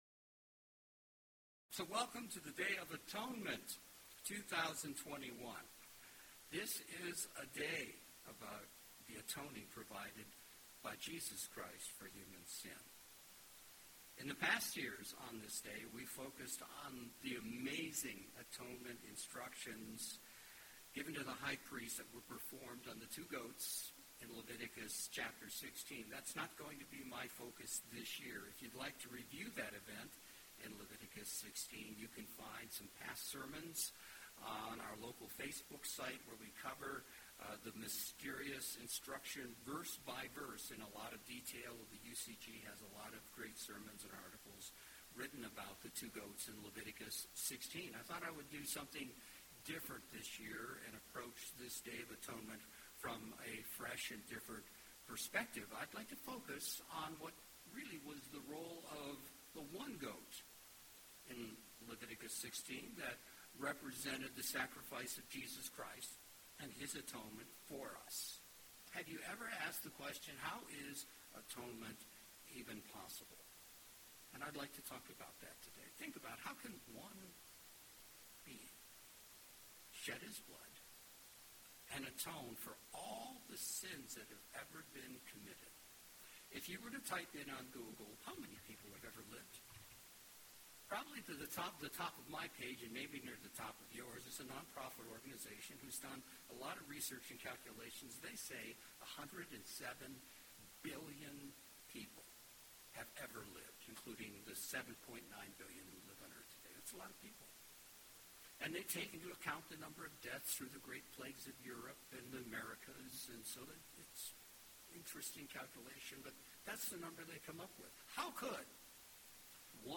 Today on this Day of Atonement I would like to discuss the theology of the concept of Atonement. How could the death of just one being... Jesus Christ... possibly "atone" for all the sins of everyone who has ever lived, or could ever live?